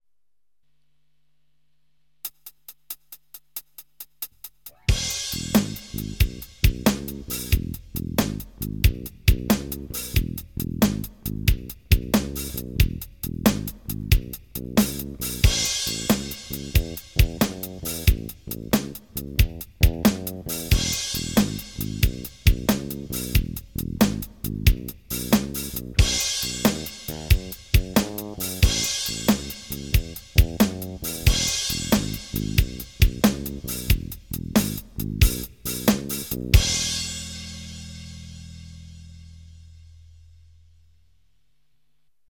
blues-kiseret-kezdoknek-5-5.mp3